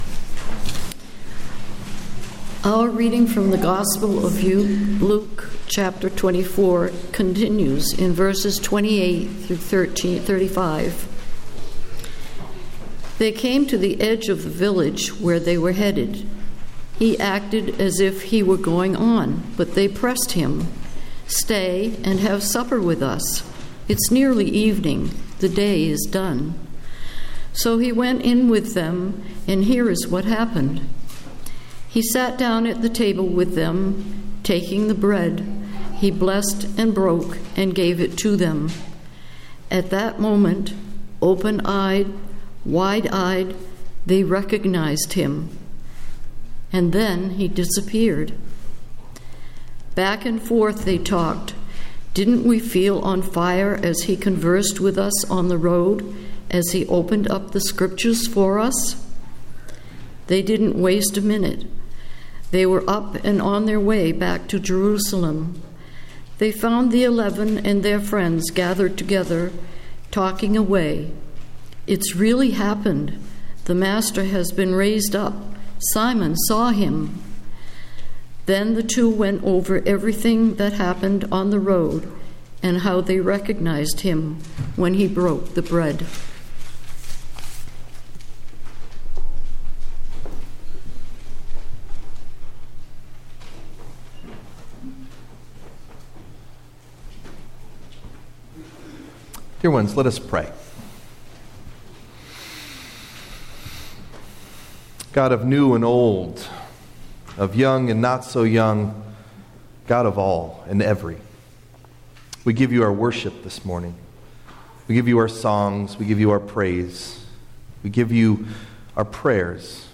Date: May 4th, 2014 (Easter 3) Message Delivered at: The United Church of Underhill (UCC and UMC) Key Text(s): Luke 24:13-25 Here is a short story/parable about a brother, a sister and a dinner party, told as part of our quarterly family service.
Message Delivered at: The United Church of Underhill (UCC and UMC)